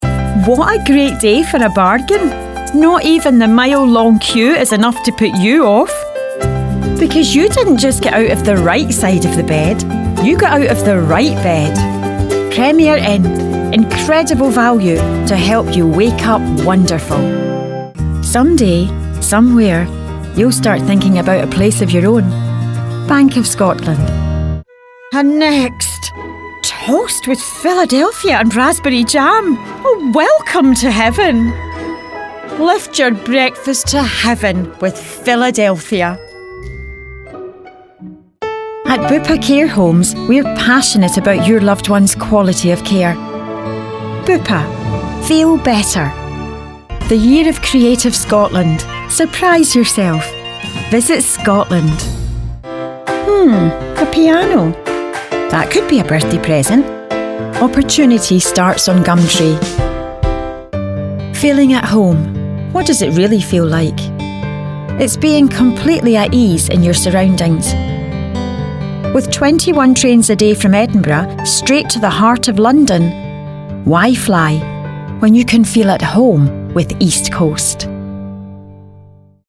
Adult
Has Own Studio
scottish | natural